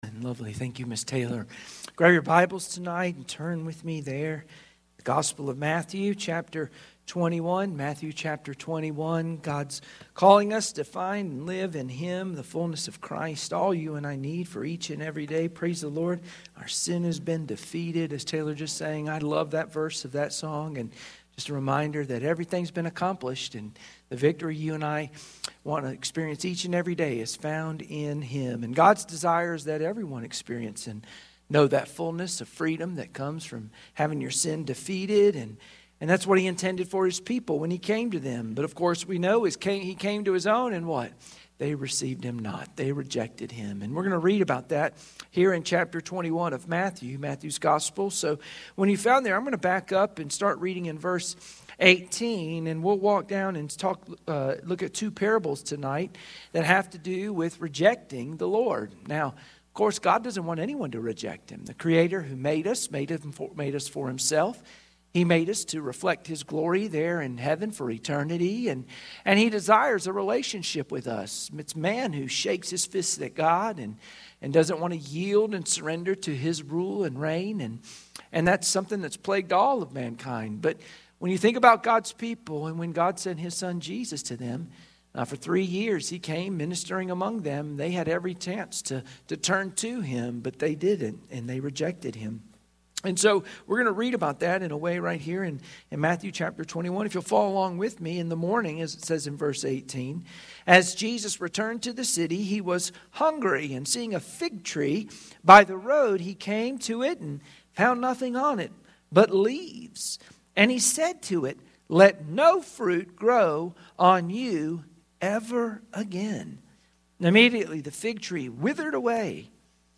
Sunday Evening Service Passage: Matthew 21:18-46 Service Type: Sunday Evening Worship Share this